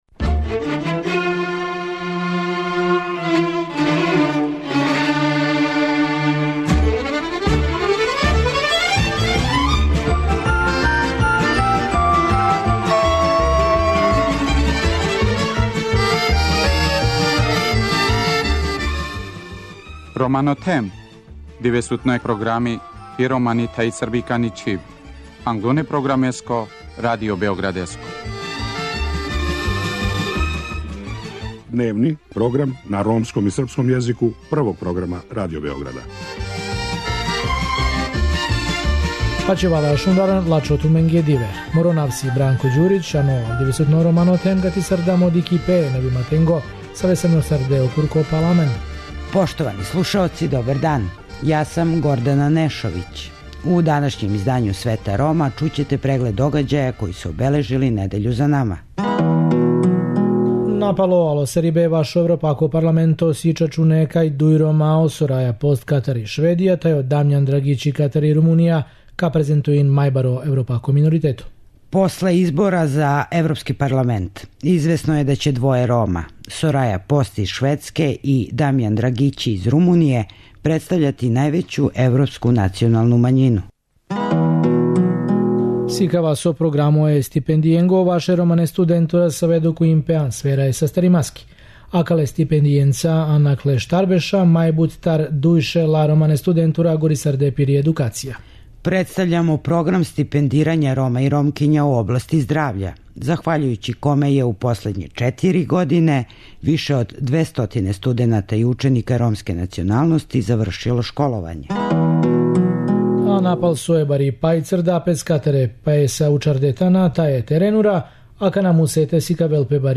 Чућете и репортажу у особама које су након поплавног таласа смештај нашле у београдској Хали Пионир.